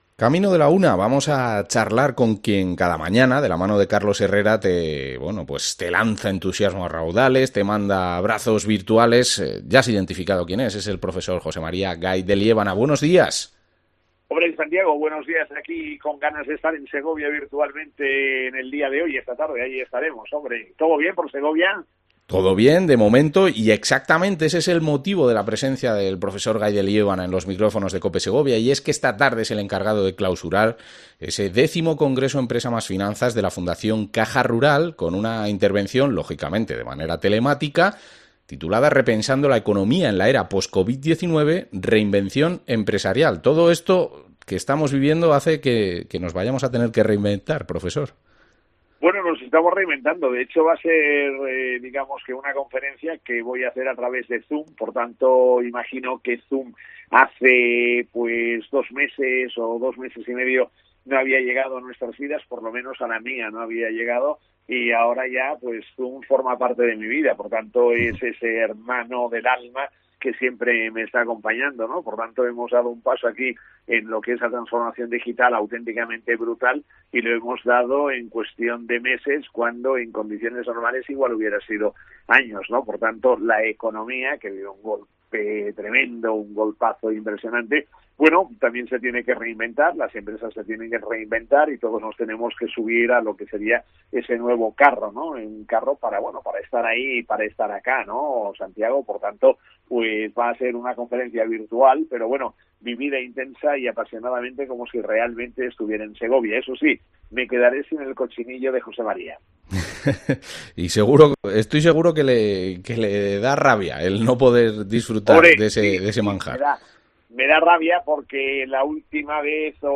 Entrevista a José María Gay de Liébana
Madrid - Publicado el 02 jun 2020, 11:35 - Actualizado 17 mar 2023, 00:04